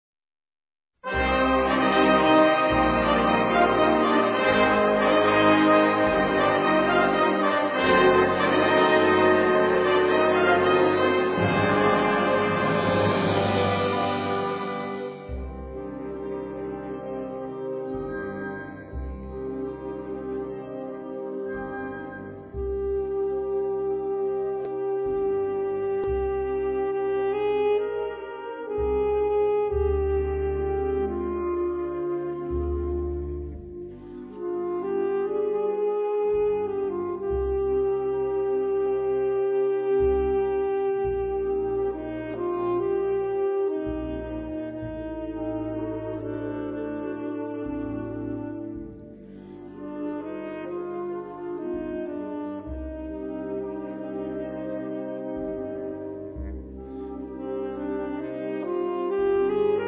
Gattung: Solo für Altsaxophon und Blasorchester
Besetzung: Blasorchester